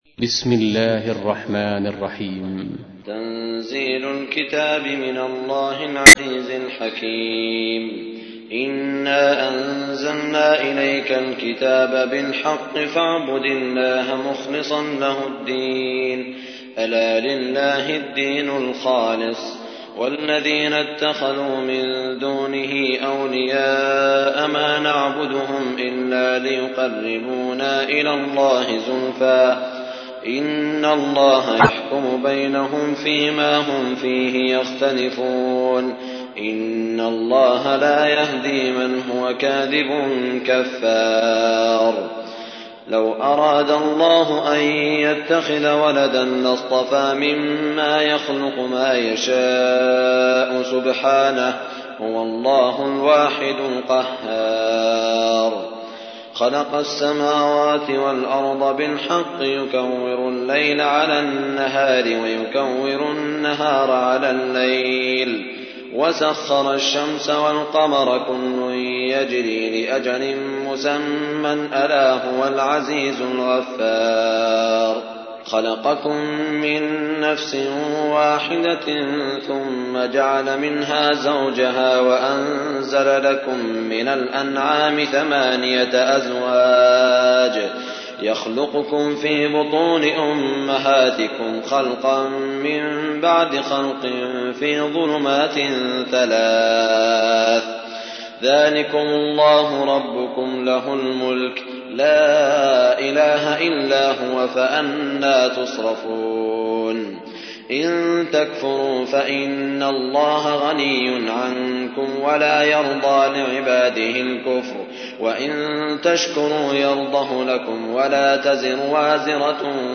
تحميل : 39. سورة الزمر / القارئ سعود الشريم / القرآن الكريم / موقع يا حسين